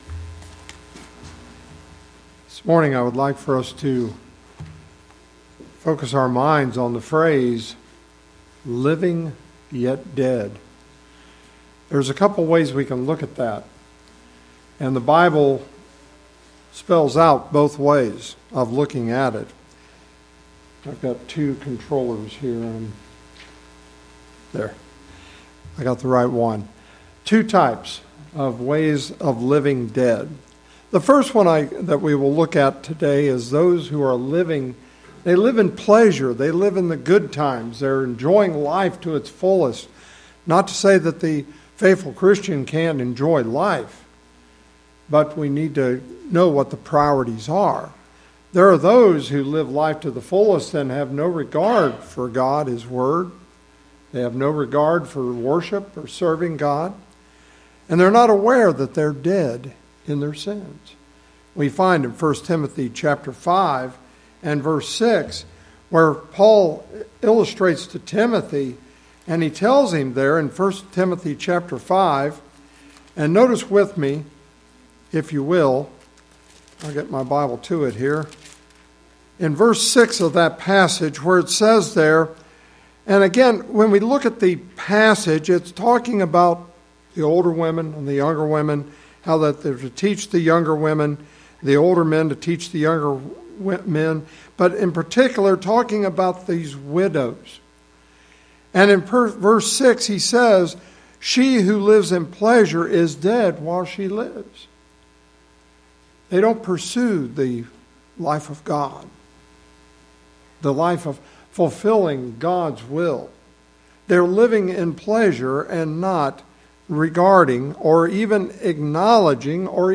Lesson Recording: